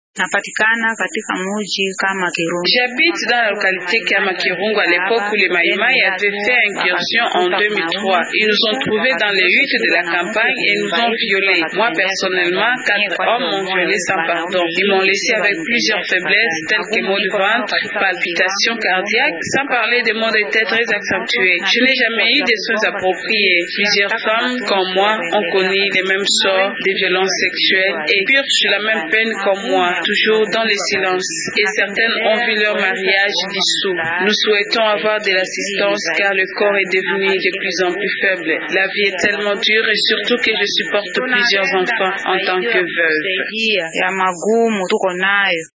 Ecoutez le témoignage d’une victime vivant à Kama, en territoire de Pangi dans le Maniema :
Temoignage-de-la-victimes-viol-a-Kama.mp3